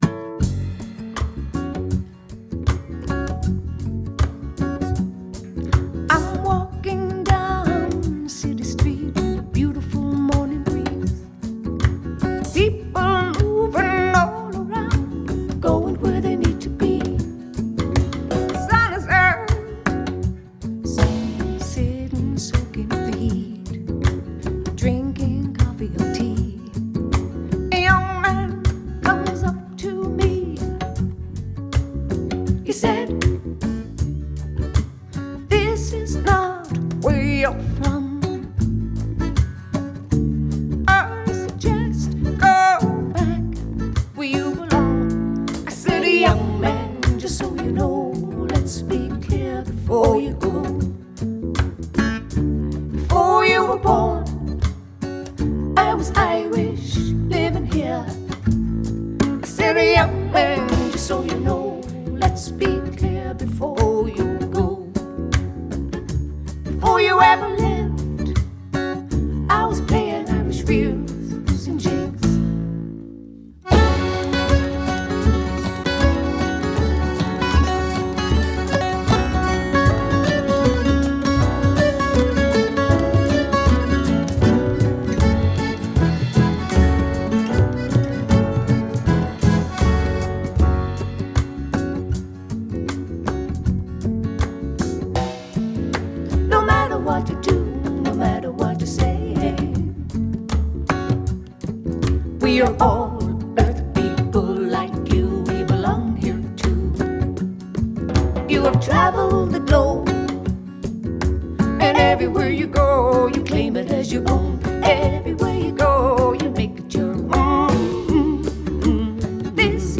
Genre: Folk/Rock